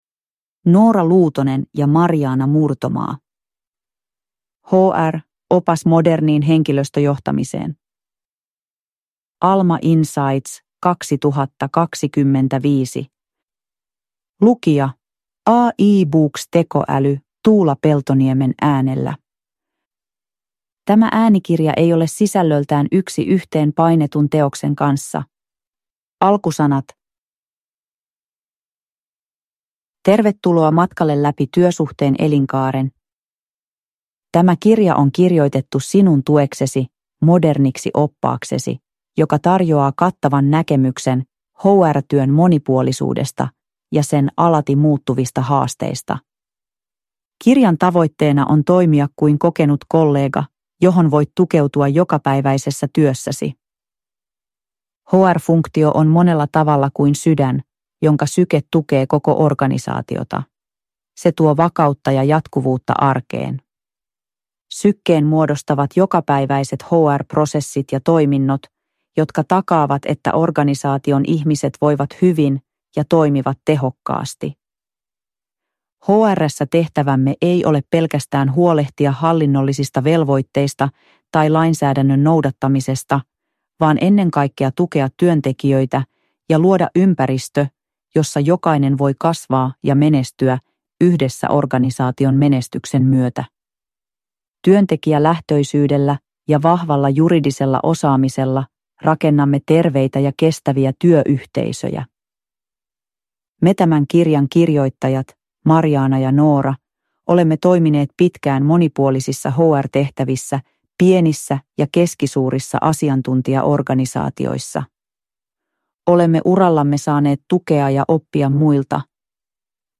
HR – Ljudbok
Uppläsare: AI (Artificial Intelligence)